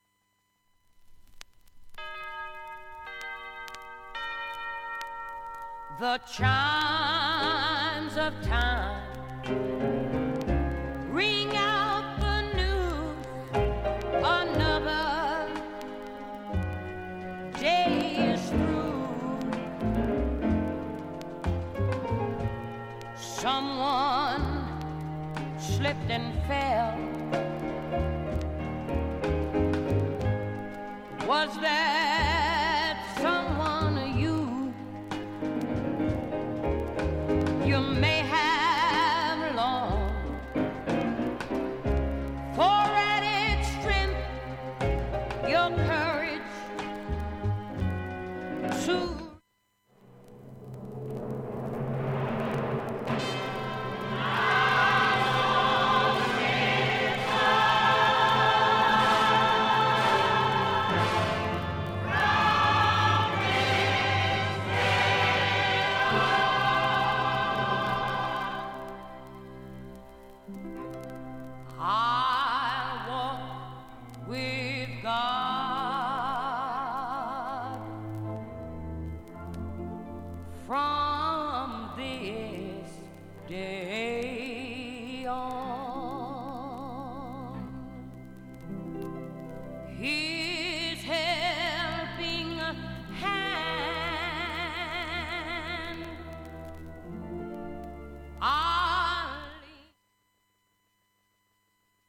音質良好全曲試聴済み。
かすかなプツが２０回出ます。
プツ出ますが、わからないレベルです。
ほか5回までのかすかなプツが５箇所、
3回までのかすかなプツが７箇所
単発のかすかなプツが７か所
アメリカのゴスペルグループ、
ポップス中心のアルバム